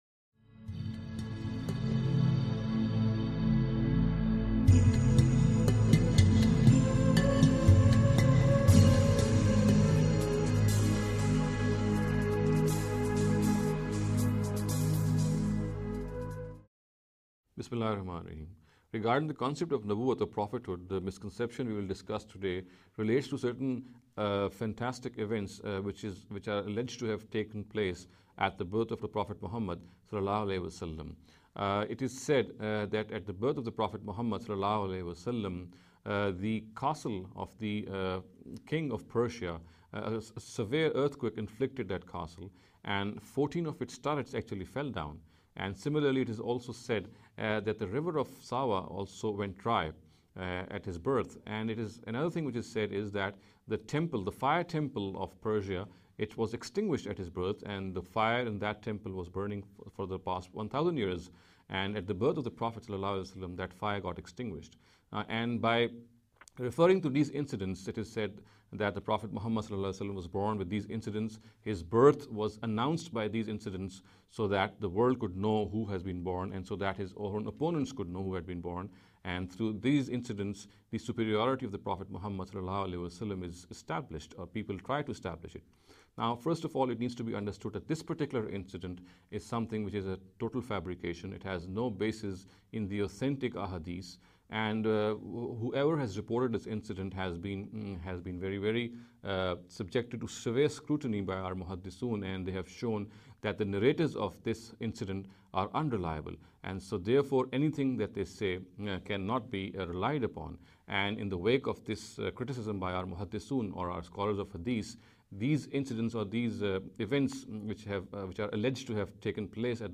In this series of short talks